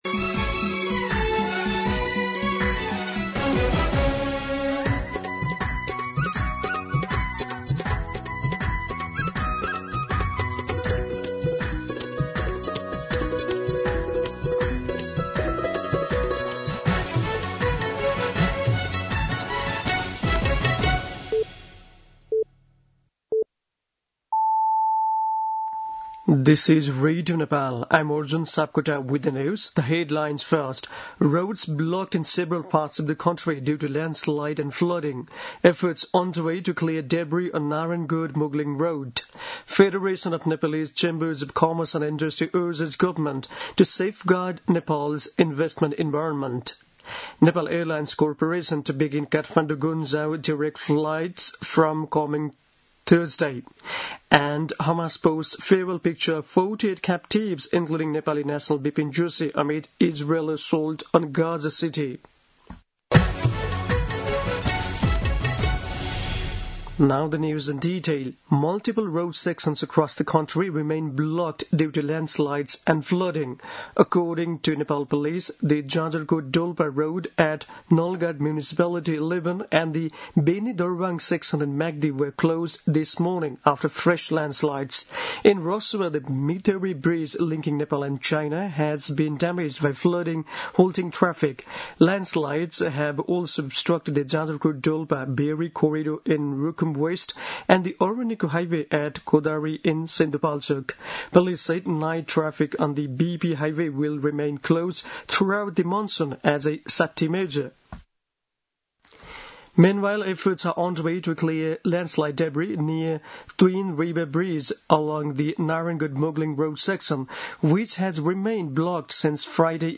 दिउँसो २ बजेको अङ्ग्रेजी समाचार : ५ असोज , २०८२